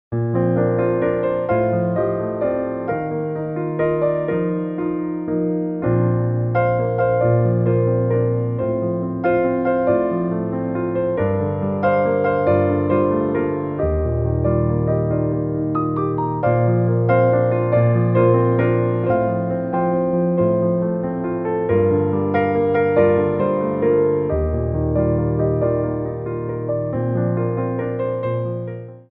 3/4 (16x8)